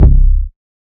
archived music/fl studio/drumkits/slayerx drumkit/808s